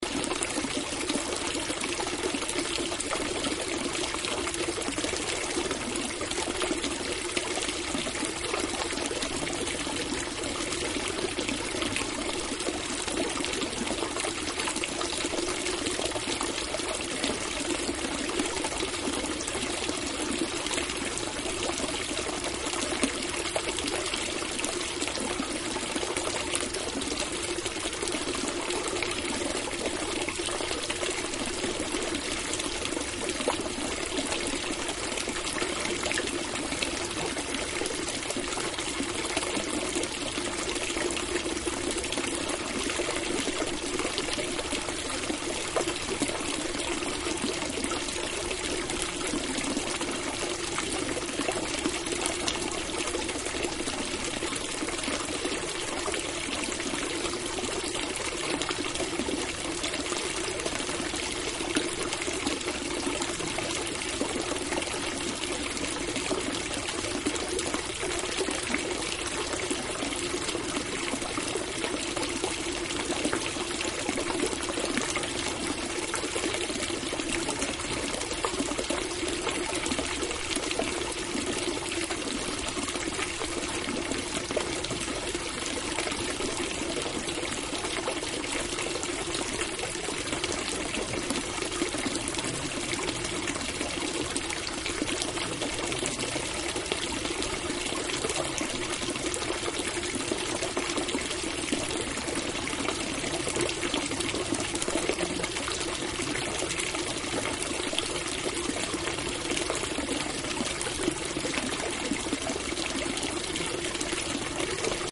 Sounds in the facade pilar in the Adarve garden.